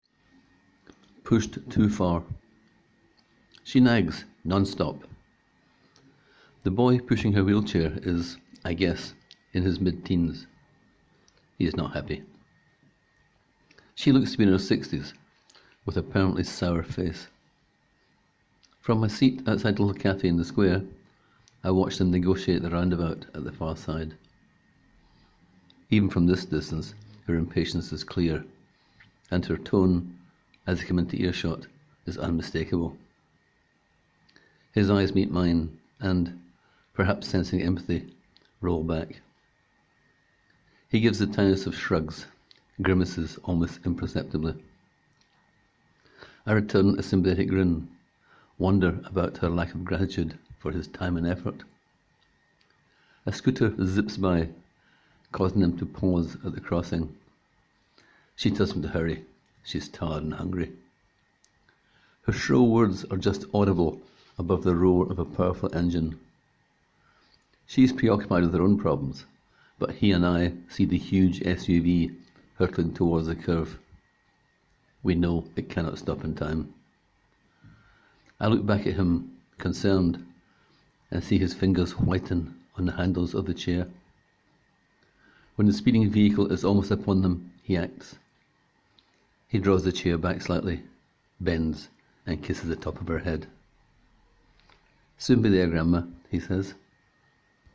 Click here to hear the author read the story: